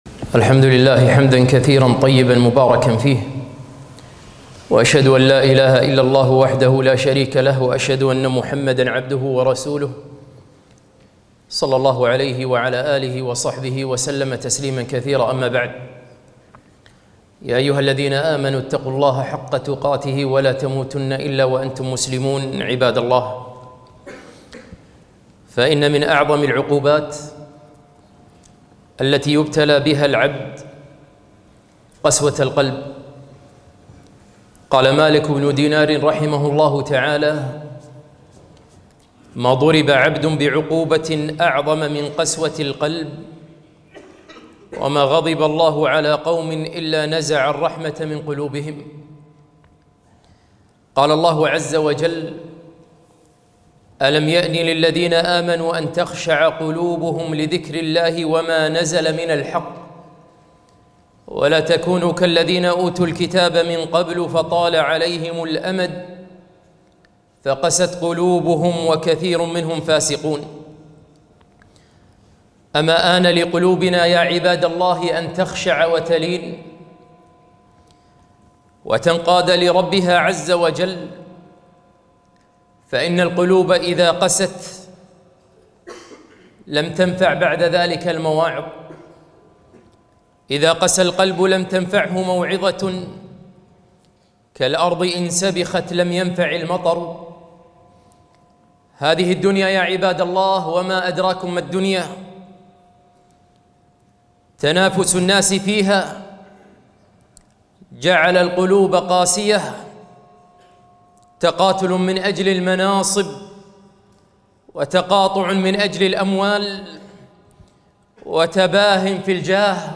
خطبة - لماذا لا تلين القلوب ؟